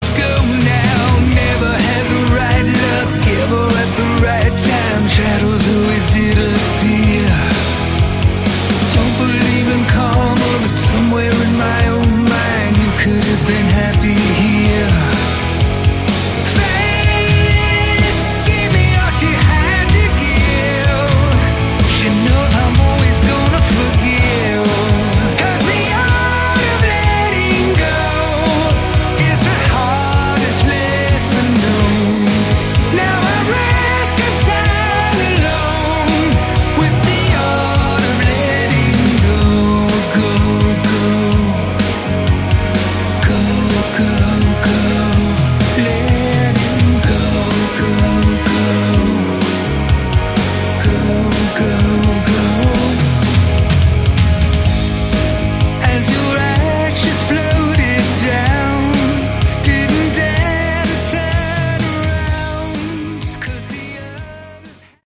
Category: Melodic Hard Rock
lead & backing vocals, keyboards, acoustic guitars
lead guitar
bass
drums
backing vocals